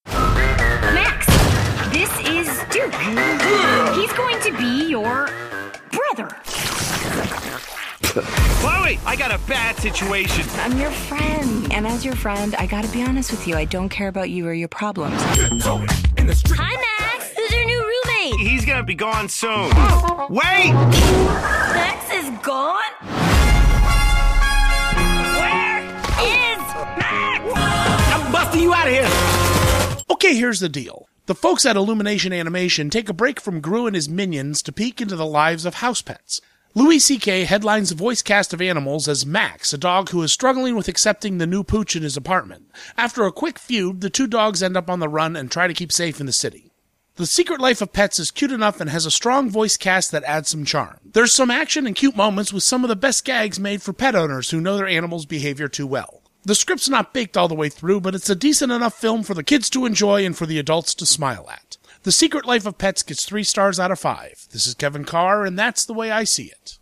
‘The Secret Life of Pets’ Radio Review